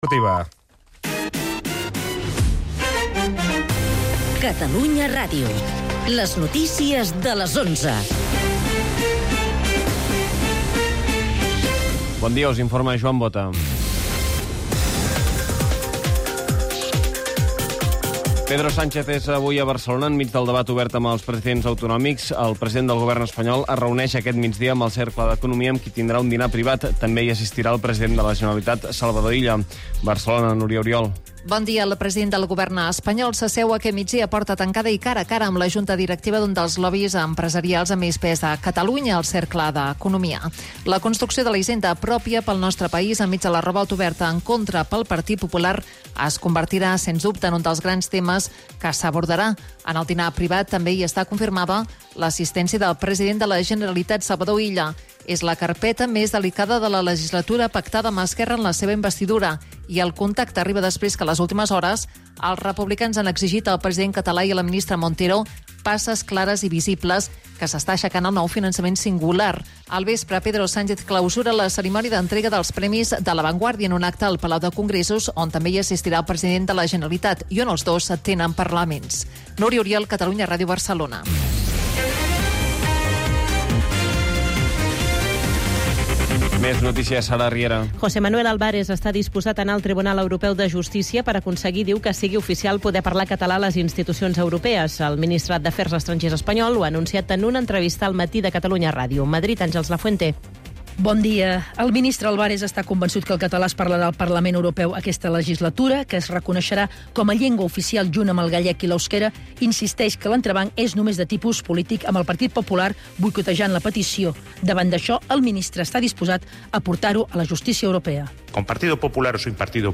El mat, d'11 a 12 h (entrevista i humor) - 30/09/2024